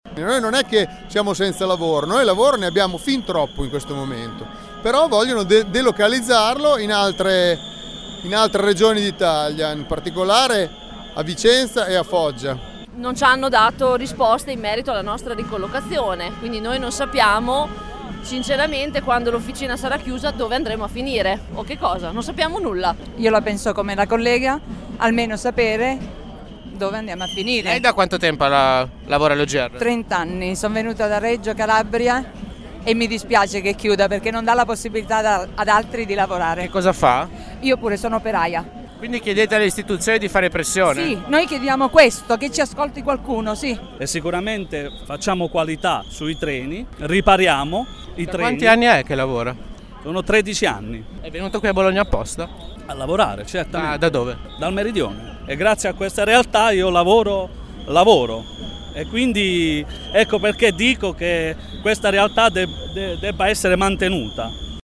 Ecco le voci di alcuni degli operai:
voci-ogr.mp3